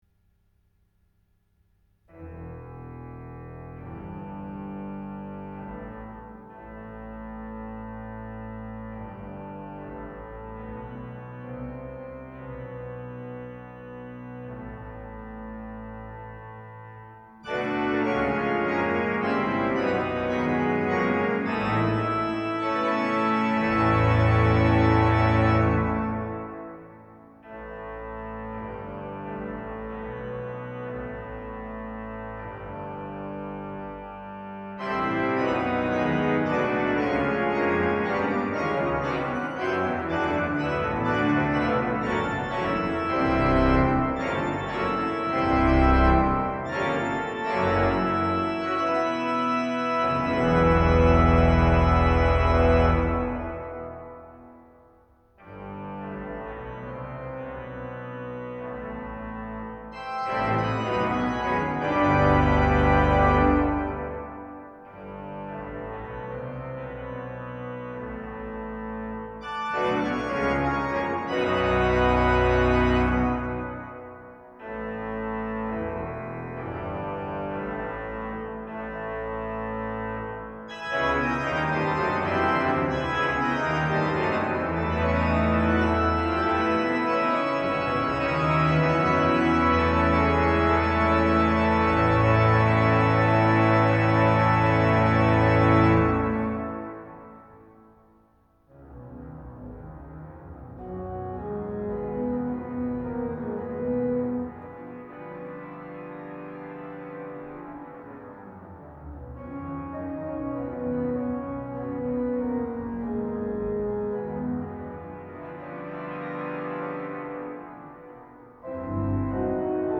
organiste